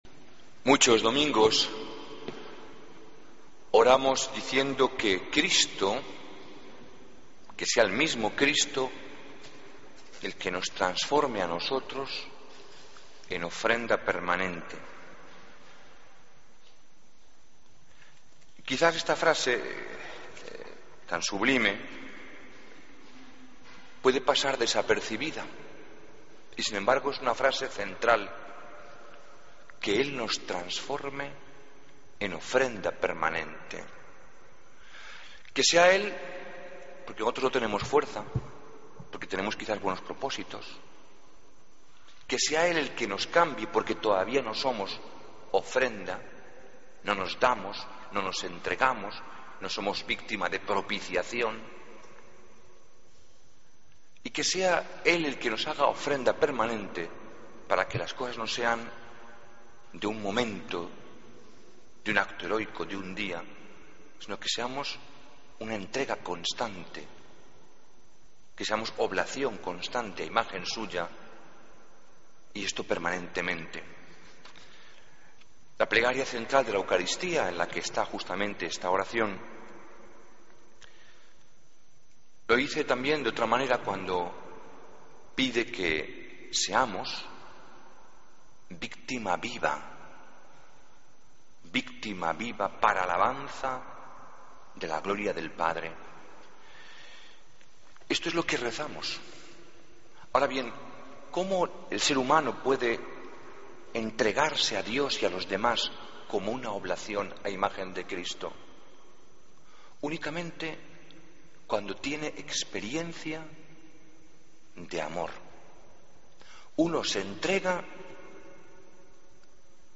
Homilía, domingo 16 de junio de 2013